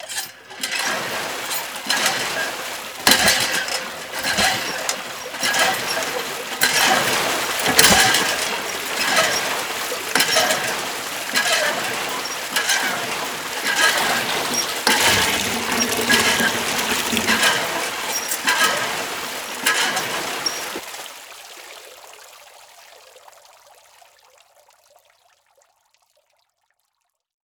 pump.wav